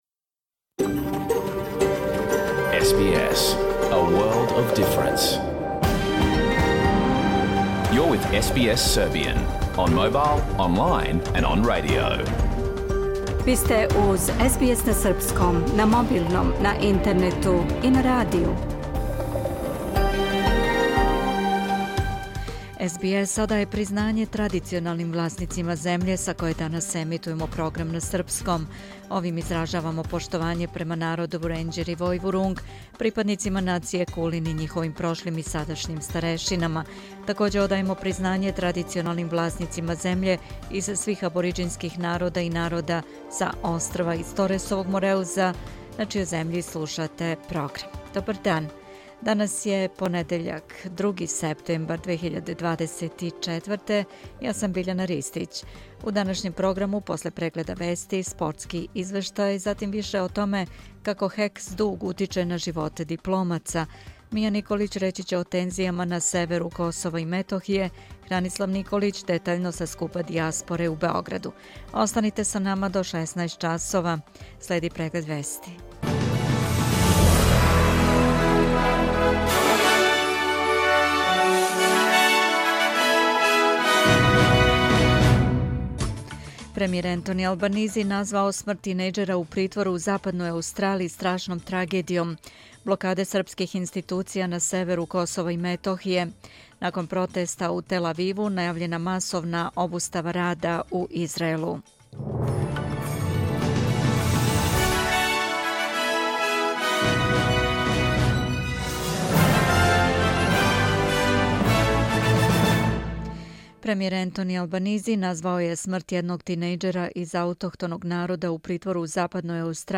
Програм емитован уживо 2. септембра 2024. године